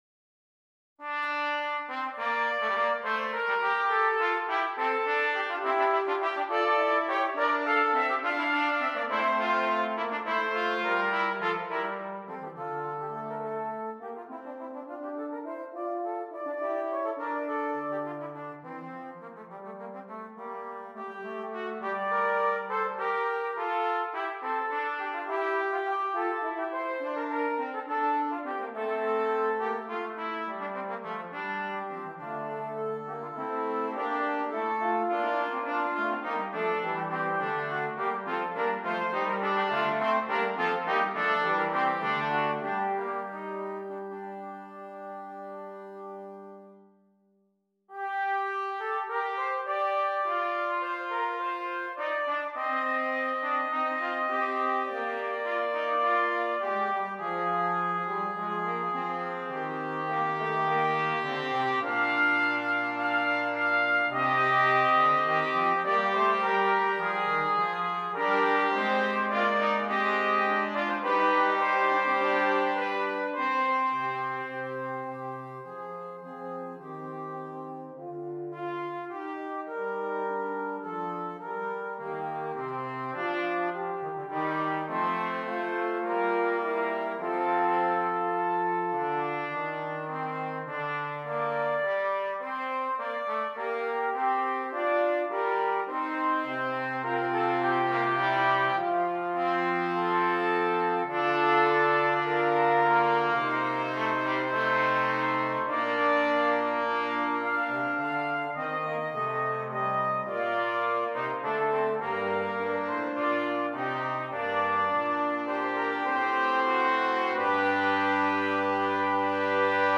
Brass Quartet